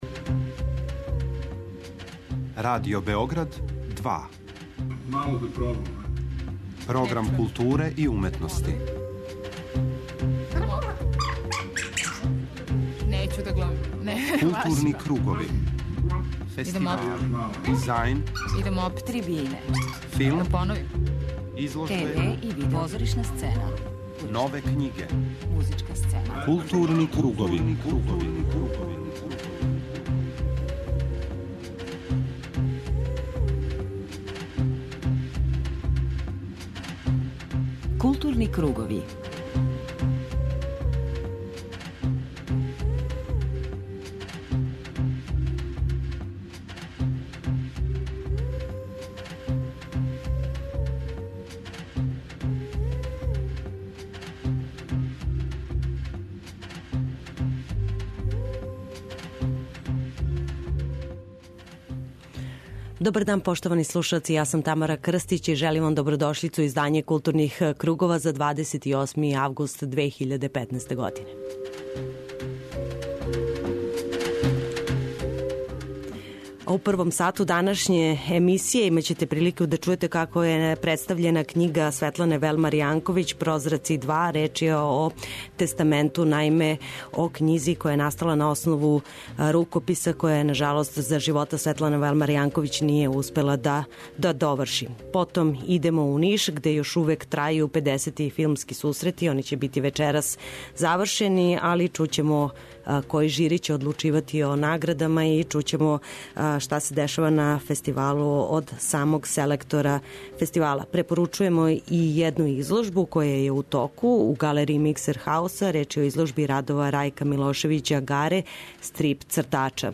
На крају темата чућете учеснике симпозијума који је био посвећен теми "Поезија и рат".